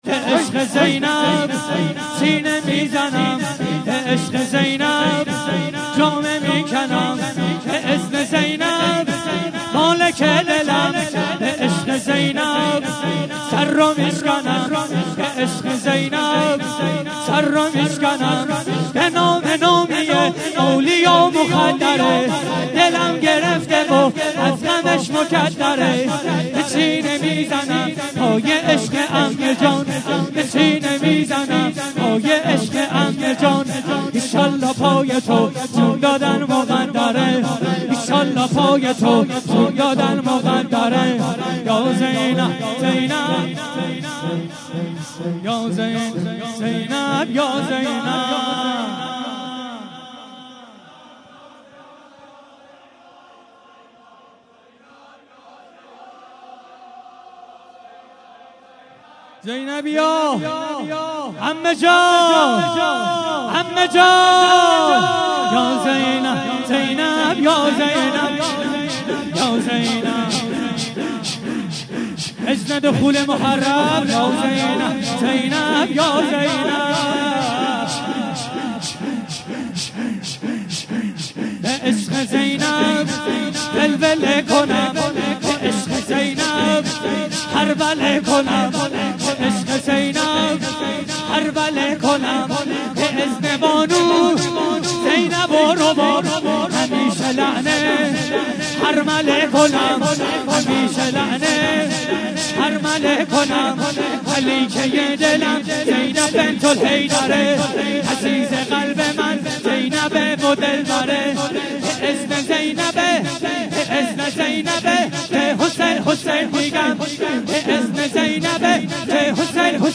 6- به عشق زینب سینه میزنم - شور